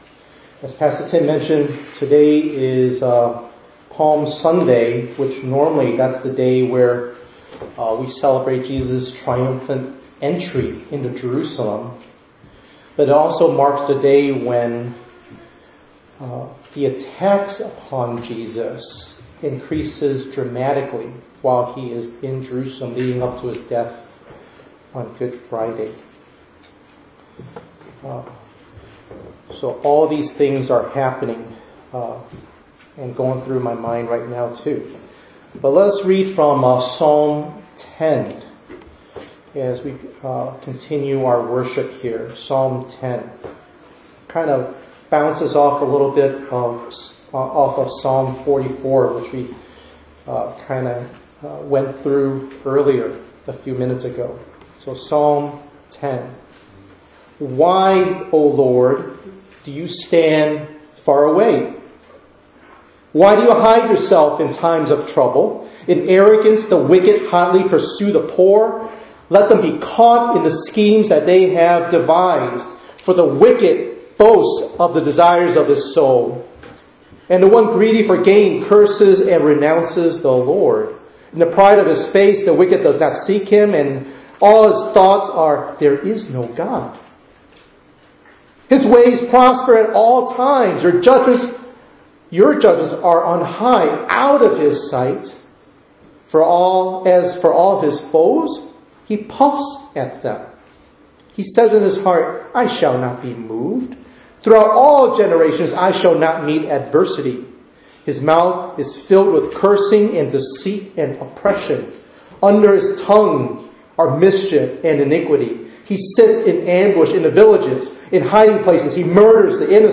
4_2_23_ENG_Sermon.mp3